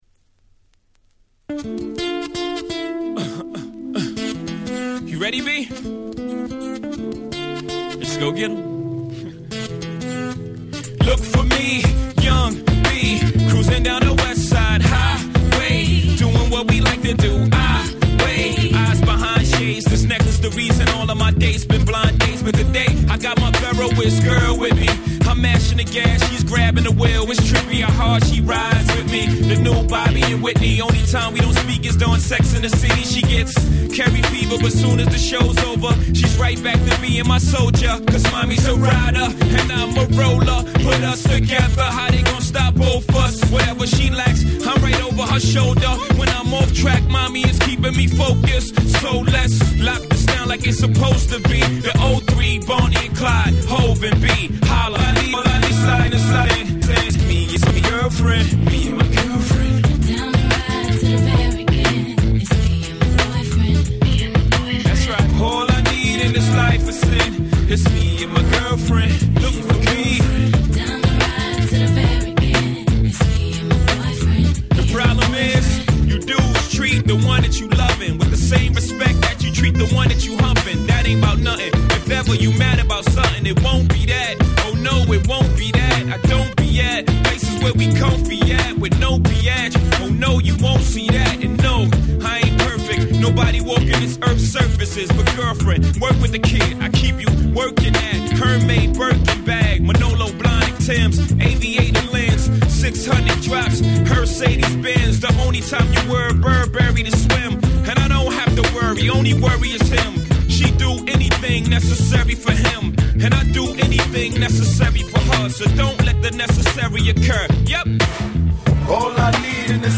02' Big Hit Hip Hop !!